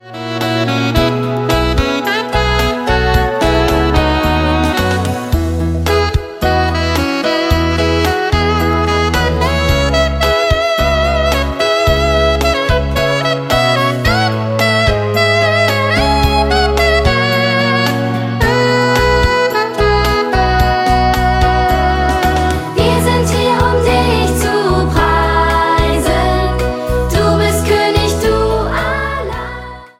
Ein Weihnachts-Minimusical